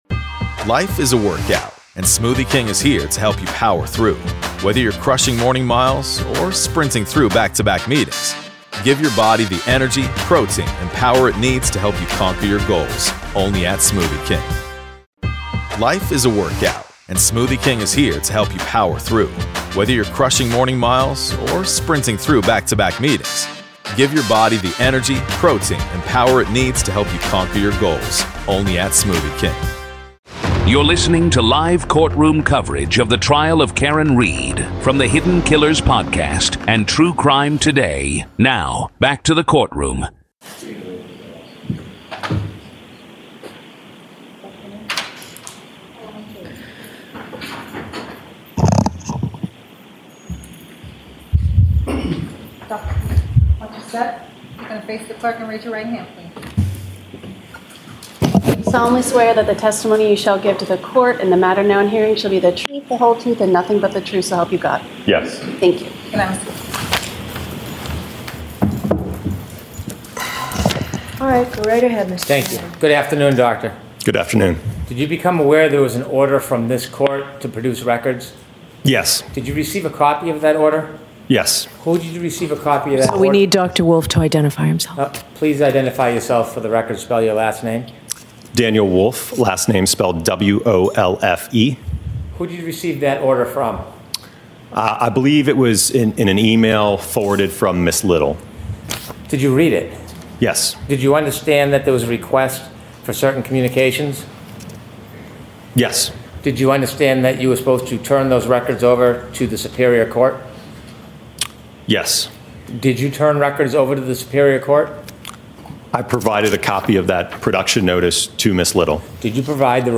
This is audio from the courtroom in the high-profile murder retrial